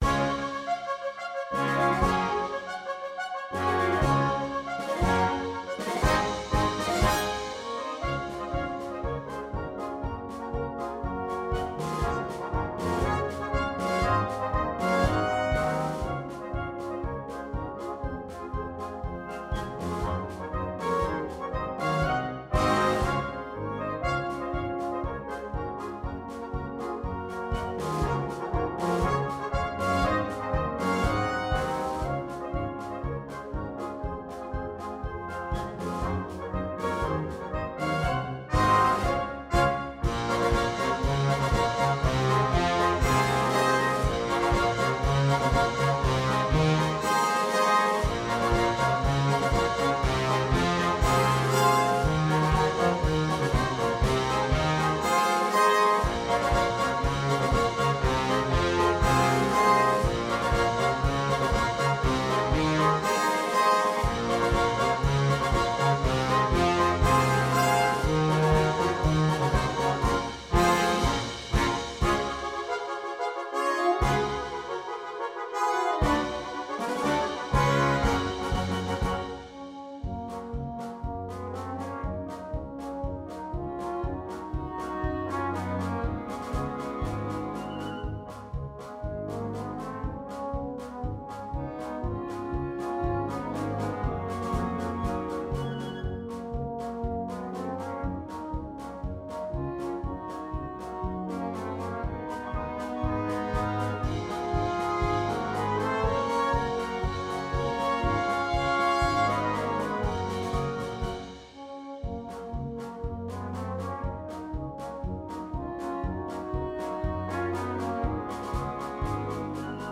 2. Egerlander-style band
Full Band
without solo instrument
March
medium - difficult